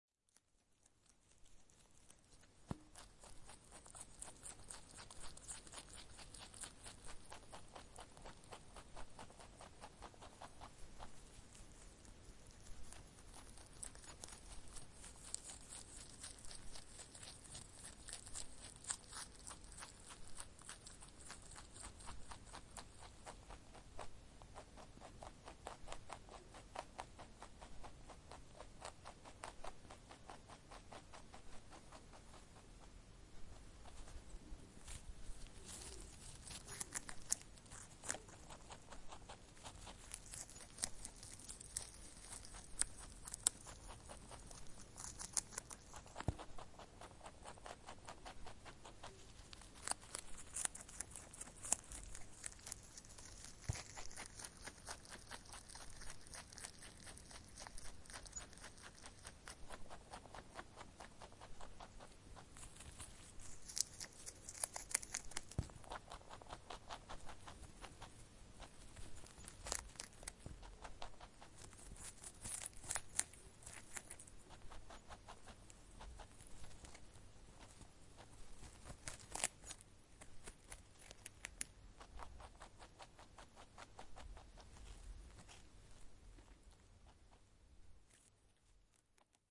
Feldhase beim Essen
Feldhase-beim-Essen-Wildtiere-in-Deutschland.mp3